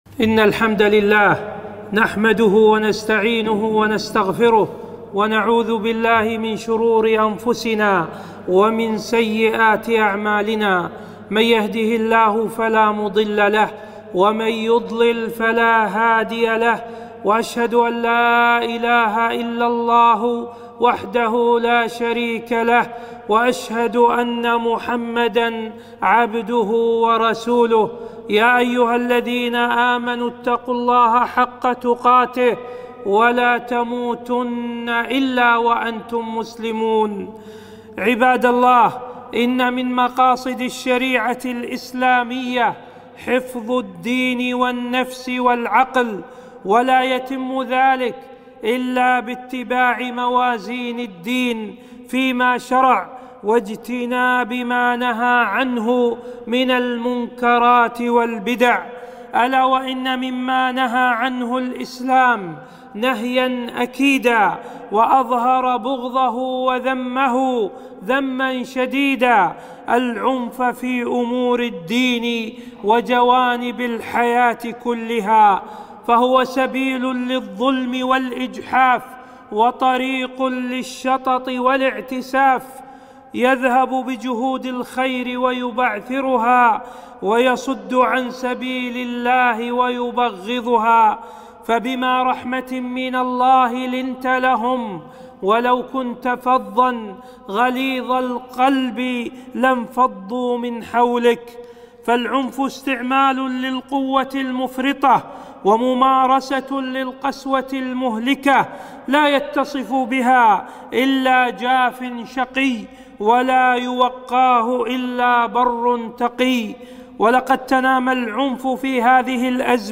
خطبة - نبذ العنف